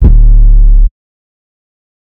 808 (Digital).wav